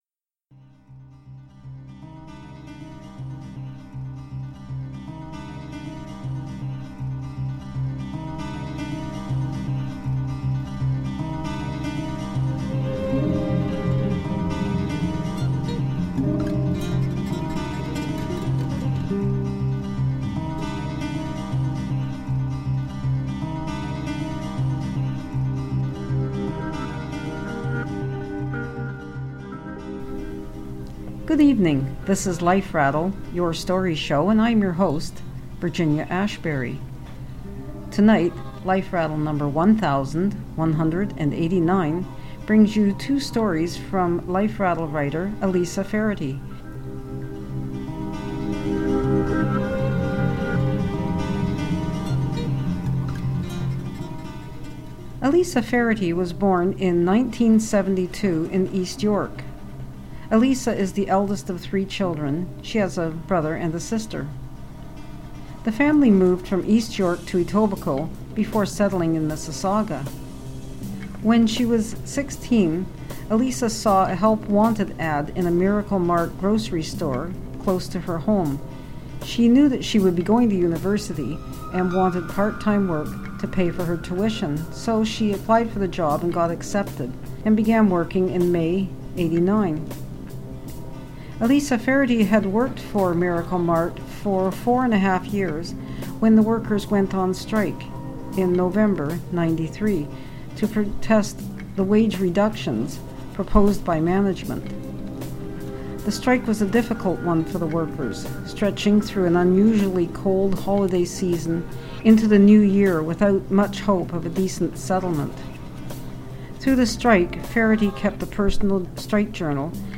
Please be advised that tonight's story contains scenes and language that some people may find offensive.